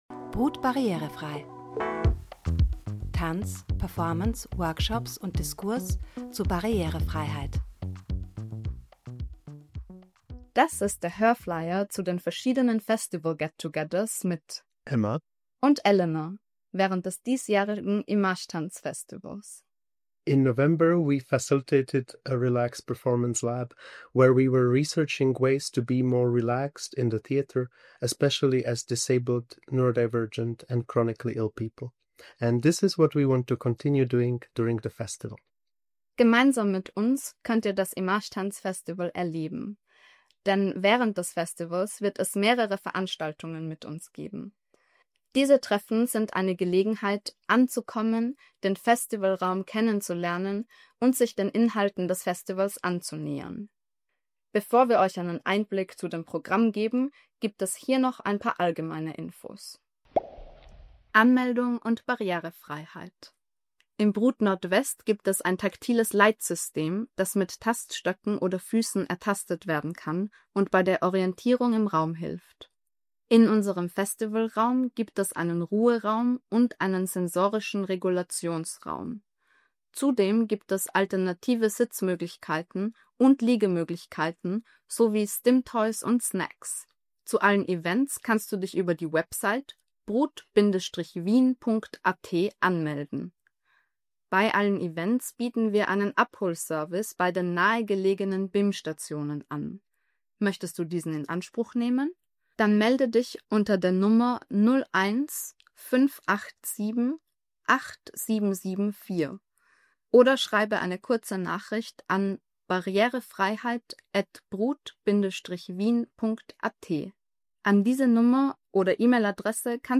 Hörflyer zum brut barrierefrei-Programm: